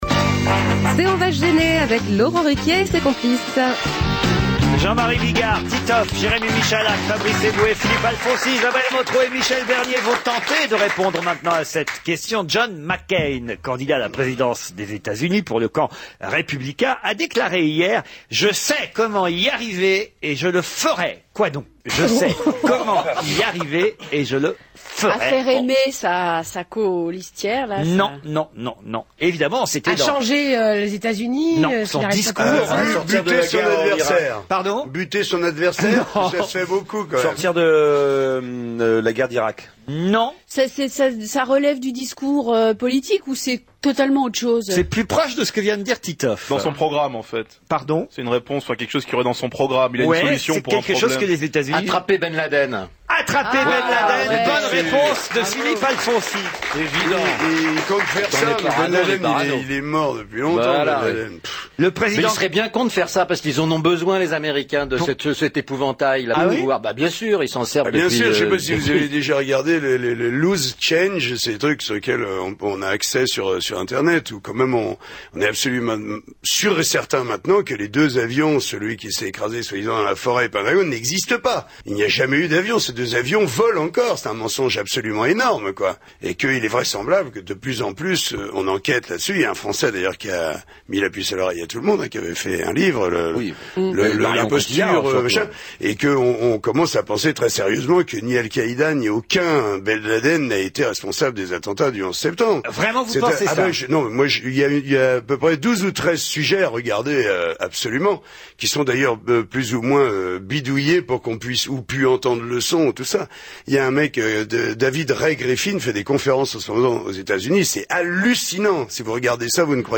A la suite d’une question sans grand intérêt de Laurent Ruquier, lors de l’émission "La bande à Ruquier" du vendredi 5 septembre sur Europe 1, Jean-Marie Bigard a mis en doute la version officielle du 11 Septembre.